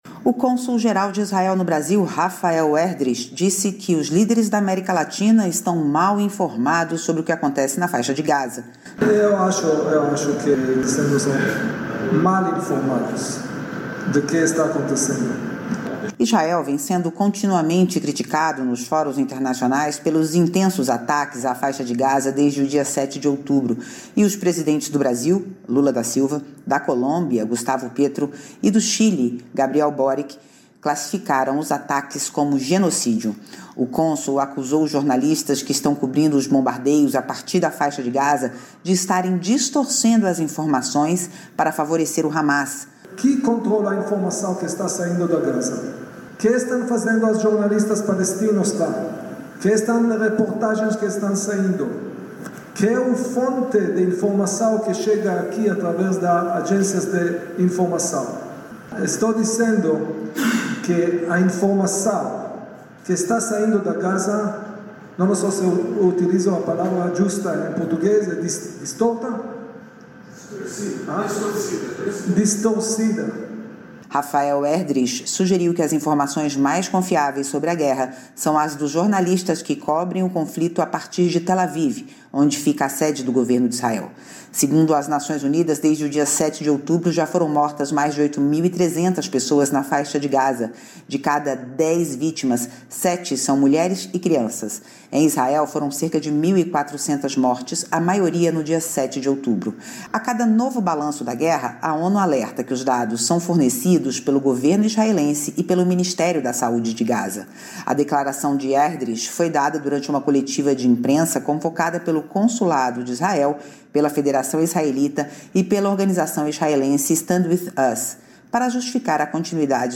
A declaração de Erdreich foi dada durante uma coletiva de imprensa convocada pelo Consulado de Israel, pela Federação Israelita e pela organização israelense StandWithUs, para justificar a continuidade dos ataques, mesmo diante da pressão internacional pelo cessar-fogo na região.